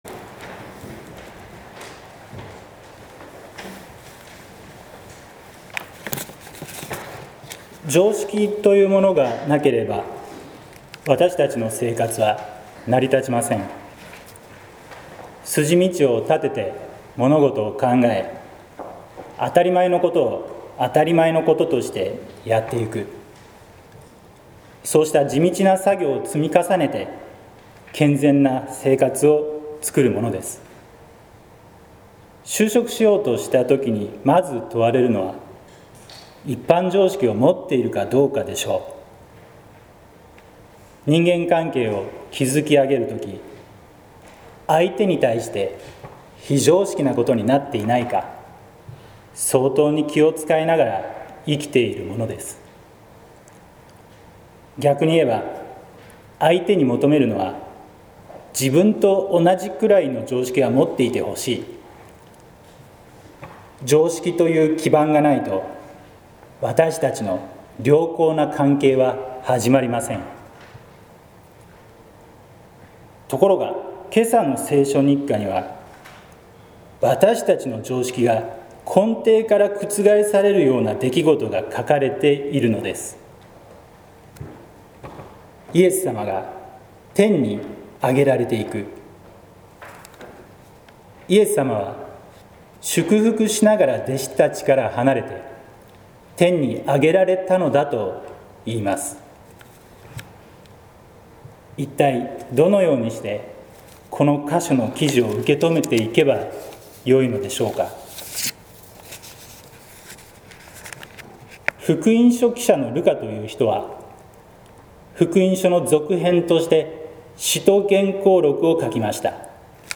説教「心の目を開く」（音声版）